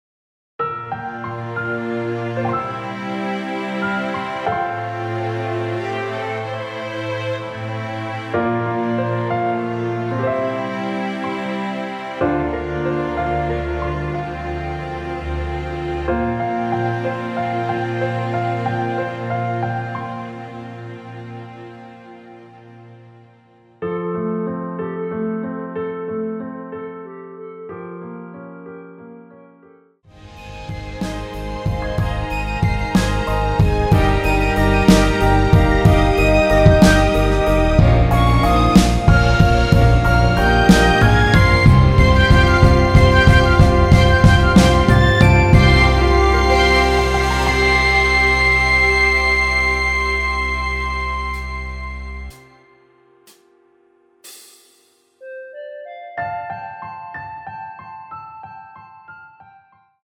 3분 14초경 무반주 후 노래 들어가는 부분 박자 맞추기 쉽게 카운트 추가하여 놓았습니다.(미리듣기 확인)
원키에서(+4)올린 멜로디 포함된 MR입니다.
앞부분30초, 뒷부분30초씩 편집해서 올려 드리고 있습니다.
중간에 음이 끈어지고 다시 나오는 이유는